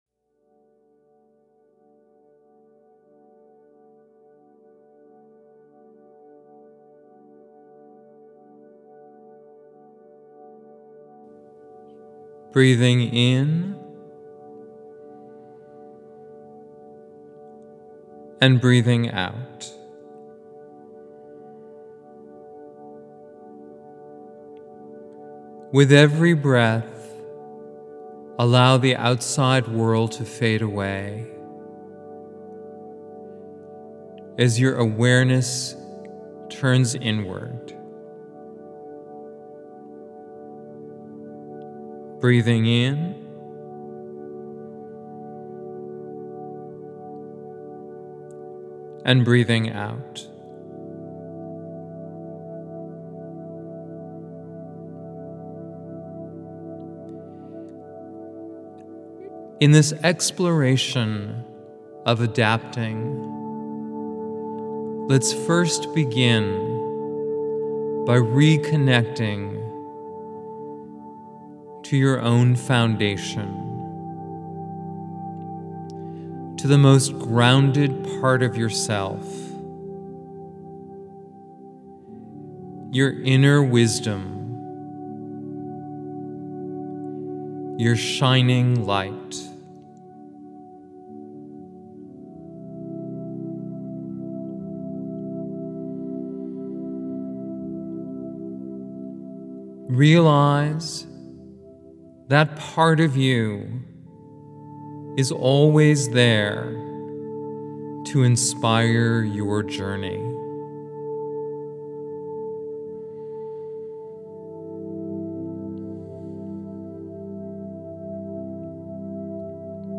Adapting-Meditation.mp3